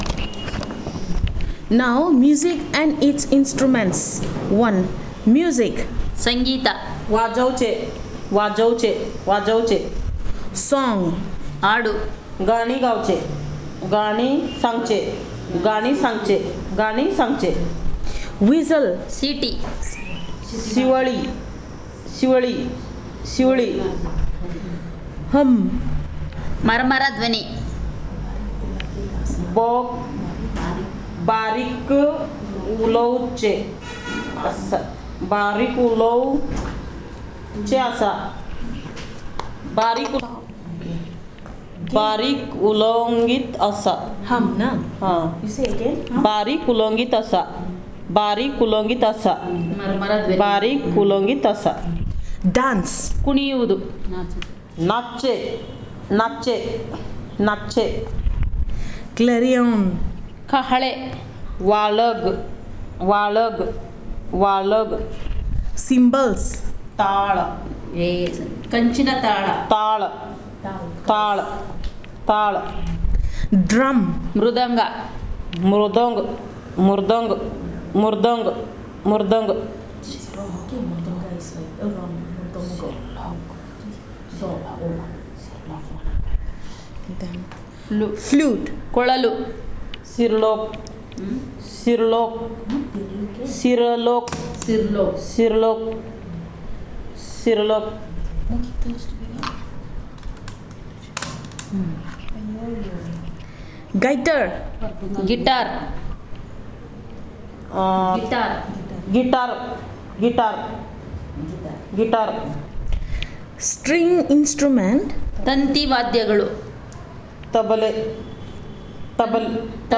NotesThis is an elicitation of words related to music, including terms for various instruments using the SPPEL Language Documentation Handbook.